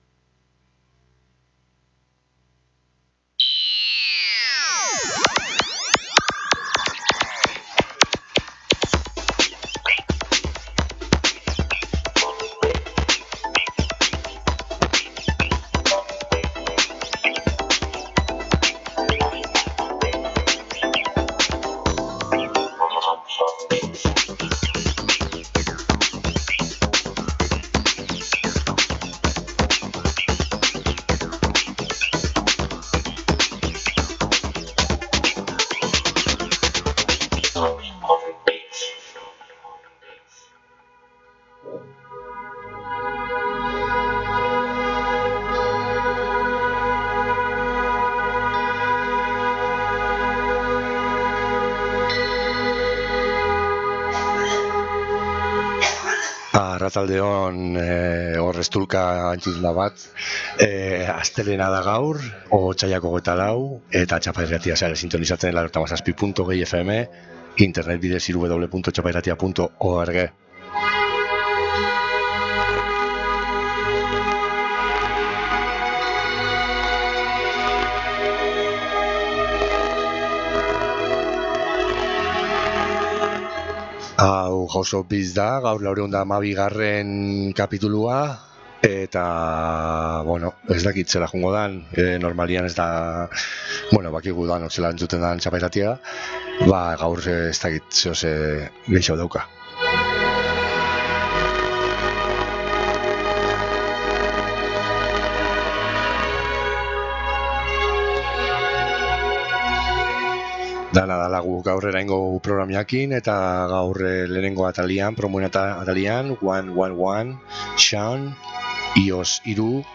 Microfestivales y otros escenarios posibles liburuaren aurkezpena